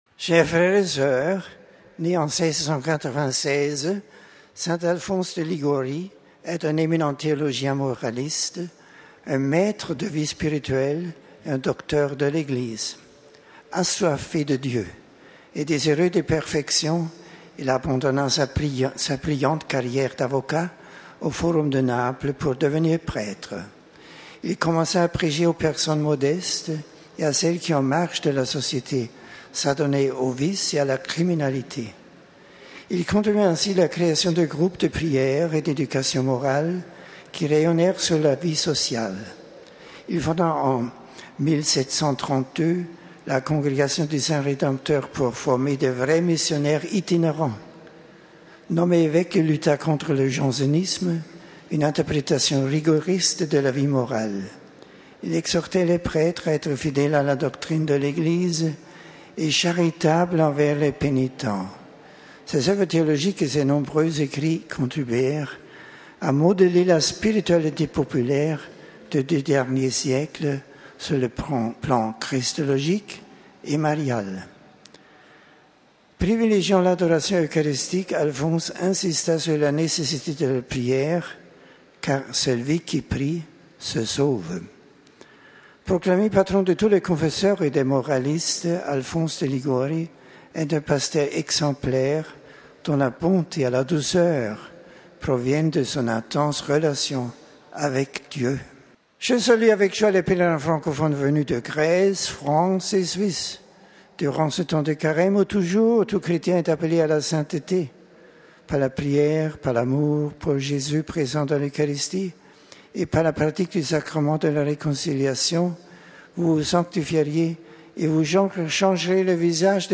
Le Pape l’a relevé, ce mercredi 30 mars à l’audience générale, en présence de quelque 10.000 personnes rassemblées sur la place Saint-Pierre. Benoît XVI avait choisi cette semaine de consacrer sa catéchèse à saint Alphonse de Liguori, docteur de l’Église et missionnaire italien du XVIII° siècle dont l’enseignement –a-t-il dit – est d’une grande actualité.
Écoutez le Pape s'adresser aux pélerins francophones RealAudio